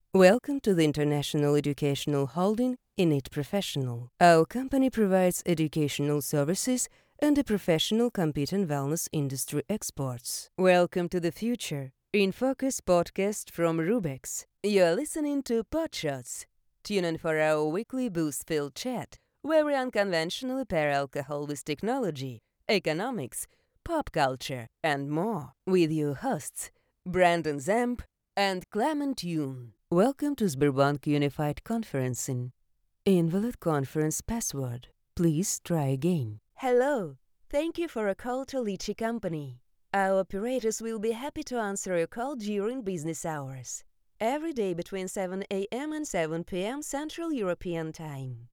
Тракт: микрофон SE Electronics x1,звуковая карта Focusrite Scarlett Solo 2nd Gen
Демо-запись №3 Скачать